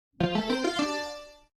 SFX_Box_Open.mp3